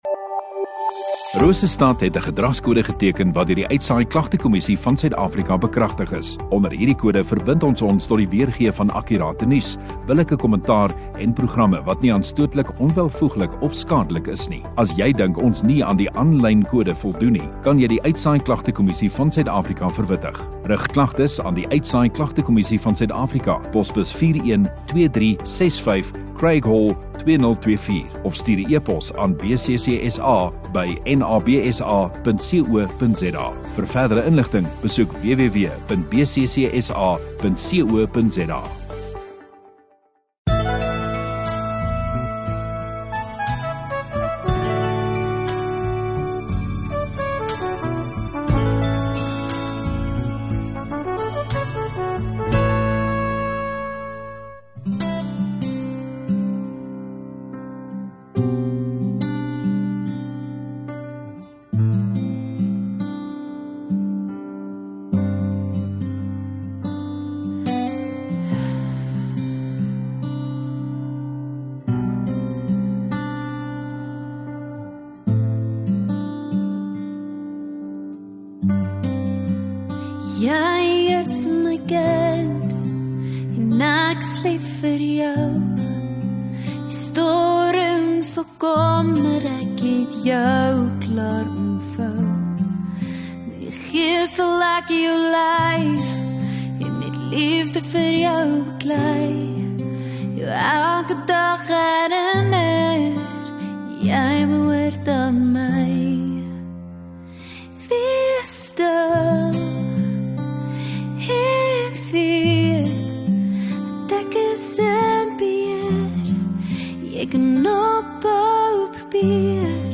27 Apr Sondagaand Erediens